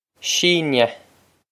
síneadh she-na
she-na
This is an approximate phonetic pronunciation of the phrase.